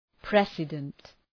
Προφορά
{‘presıdənt}